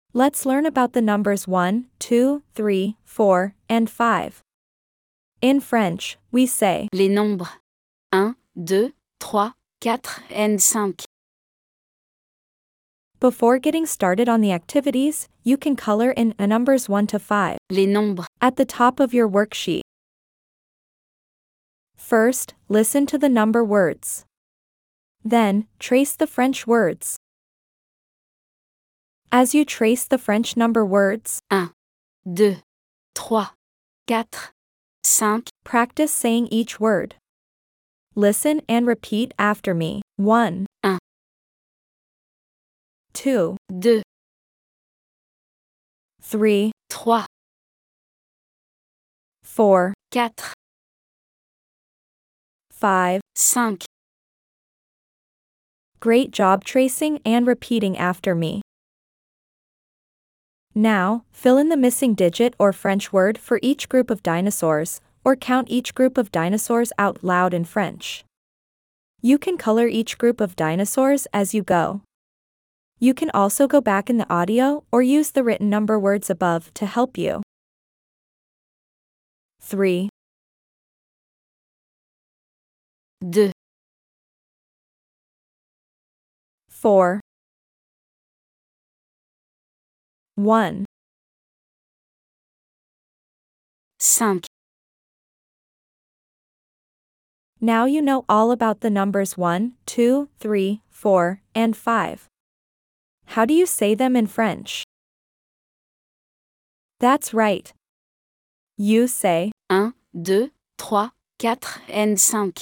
If you haven’t received your first box yet, you’ll find a free introduction to number words and an accompanying audio file for pronunciation at the end of this post.